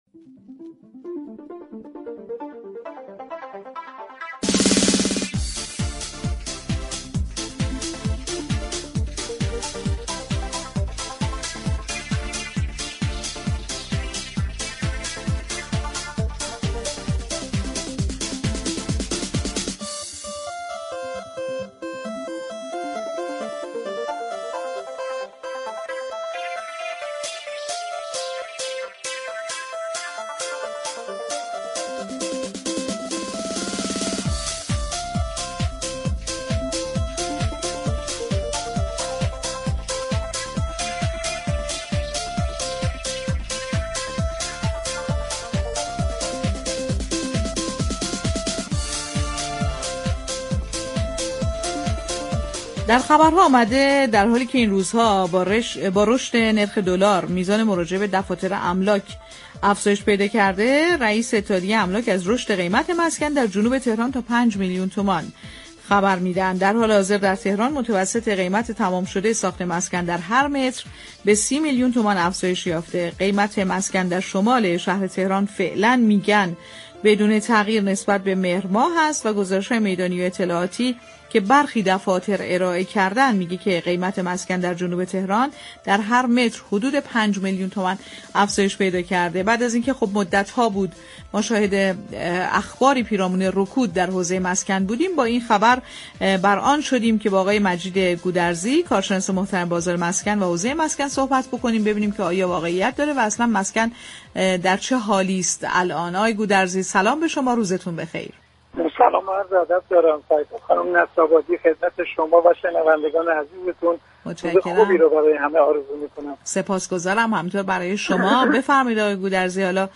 گفت‌و‌گو